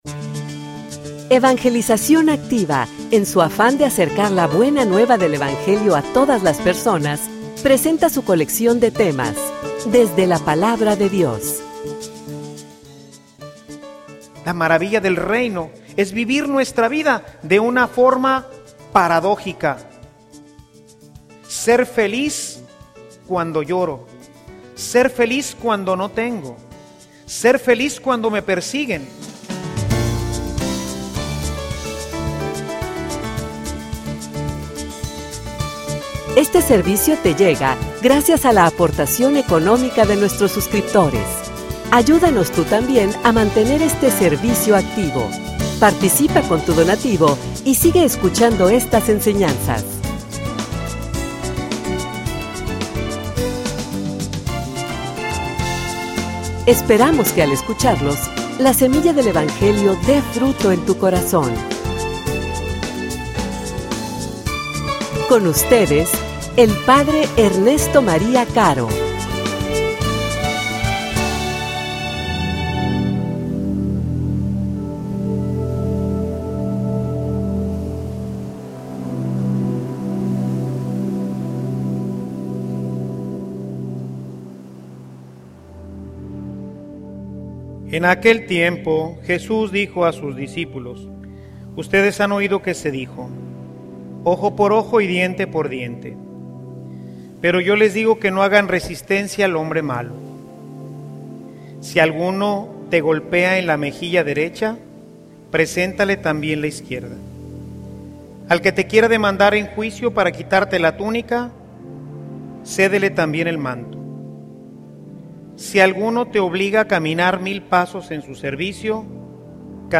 homilia_La_ultima_paradoja_del_Reino.mp3